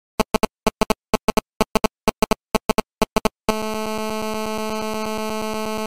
That sound your old pc speaker does when you receive a phone call.mp3